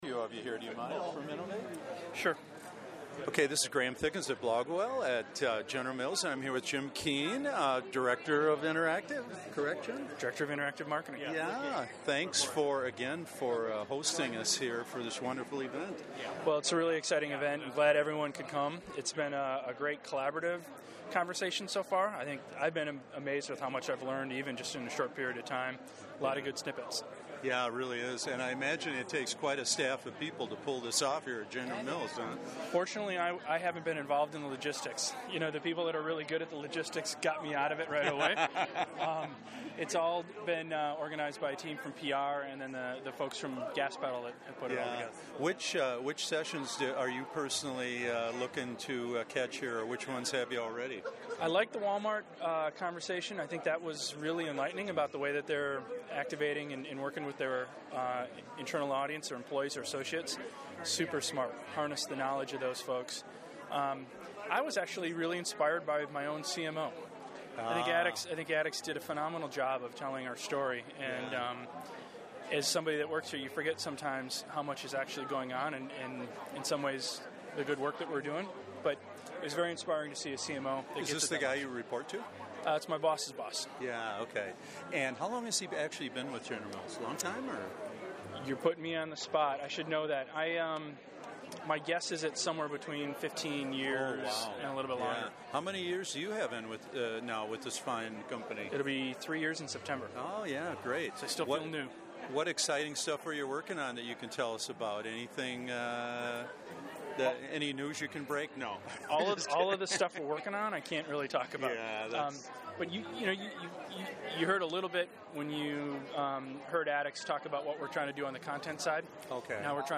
My Interviews: I took along my trusty studio-grade handheld recorder (the Olympus LS-10) and grabbed seven brief audio interviews before and after the sessions, and during breaks.